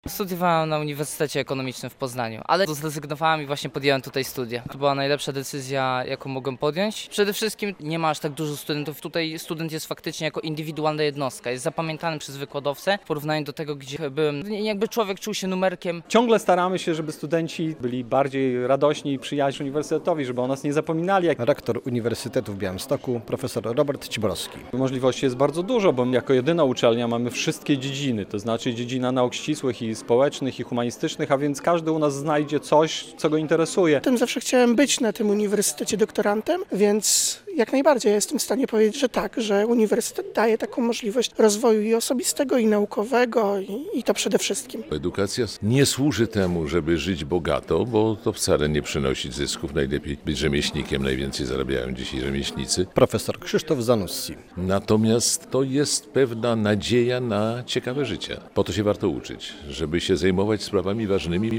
Inauguracja nowego roku akademickiego na Uniwersytecie w Białymstoku - relacja